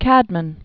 (kădmən) Died c. 680.